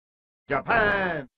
japan-street-fighter-2-turbo-sound-effect-free.mp3